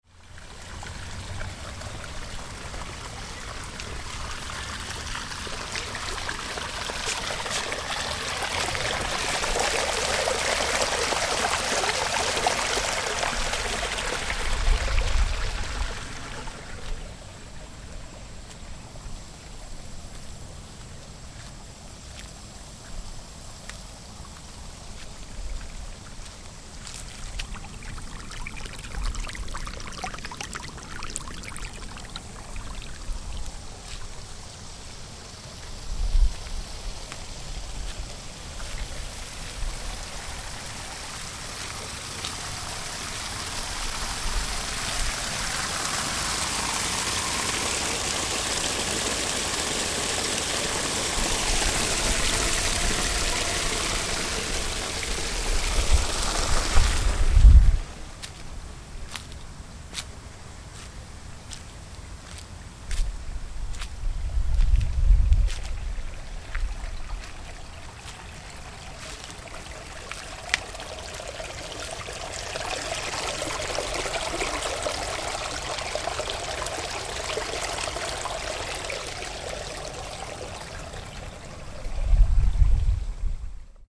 Bon, pour les impatients (comme moi), voici quelques liens ou vous pourrez écouter des extraits de prises son réalisés avec le H2, ainsi que quelques petits testes.
bbg-stream.mp3